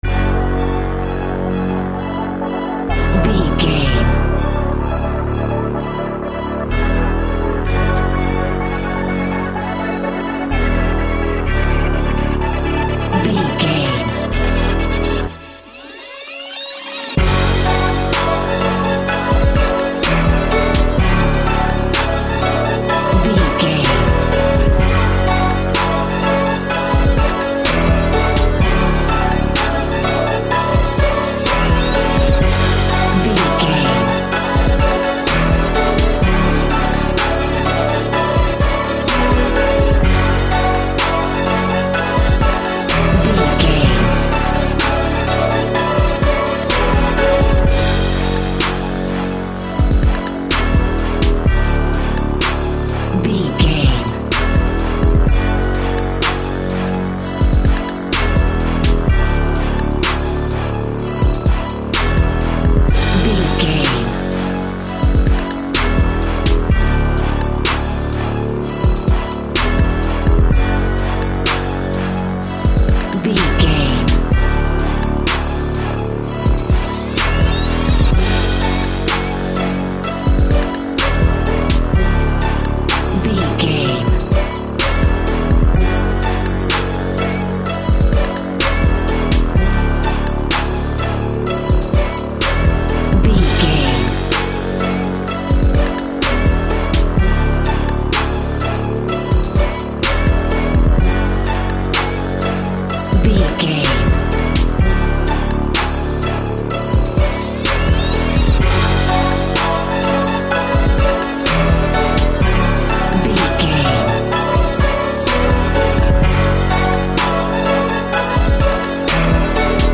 Orchestral Epic Hip Hop Music Tune.
Epic / Action
Aeolian/Minor
F#
chilled
laid back
hip hop drums
hip hop synths
piano
hip hop pads